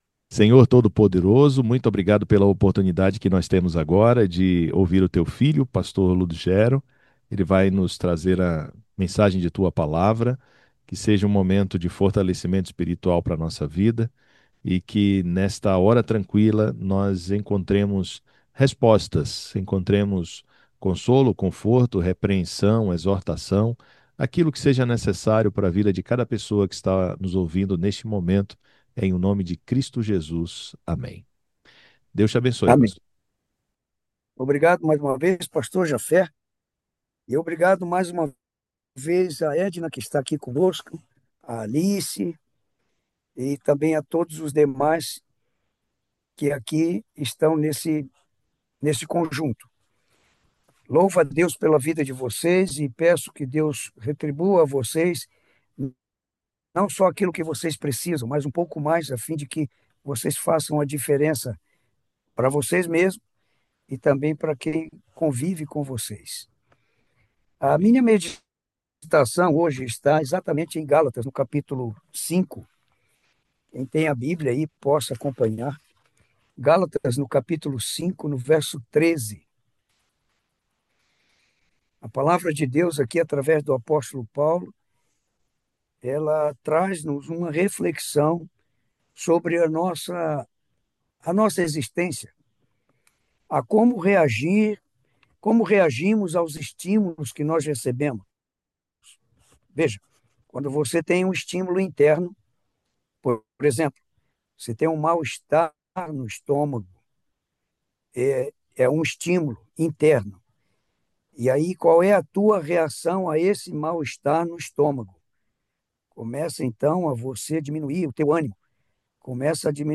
A Hora Tranquila é um devocional semanal.